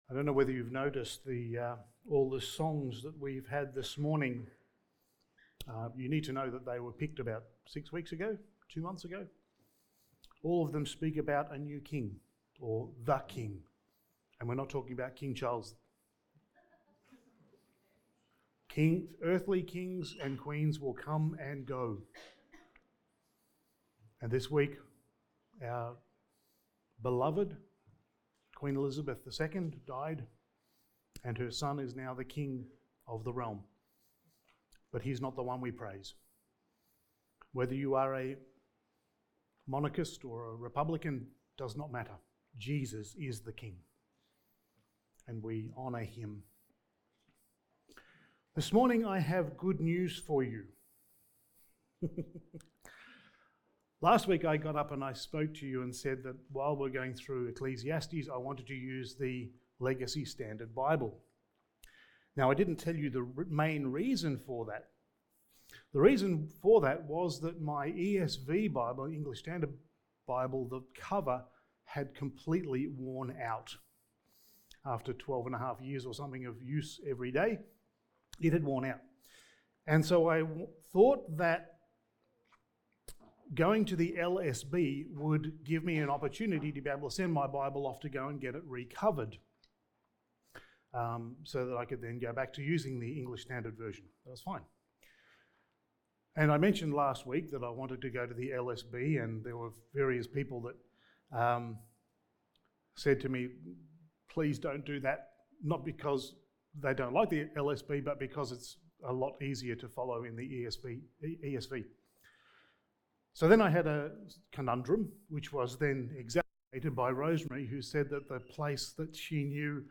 Passage: Ecclesiastes 1:3-11 Service Type: Sunday Morning